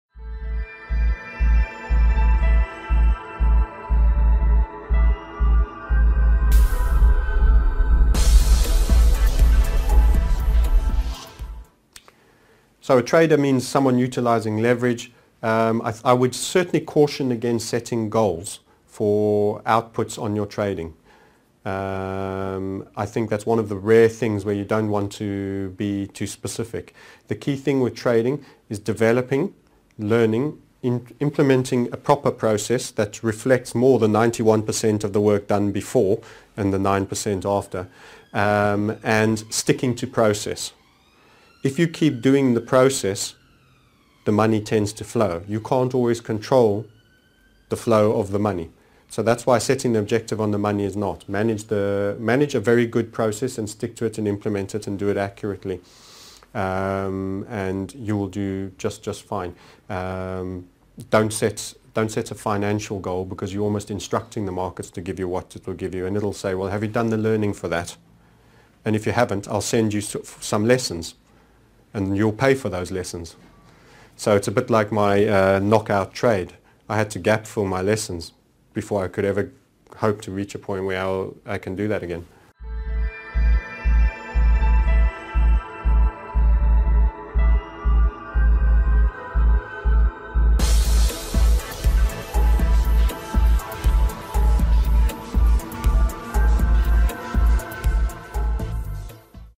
29 What returns should a trader be happy with TMS Interviewed Series 29 of 32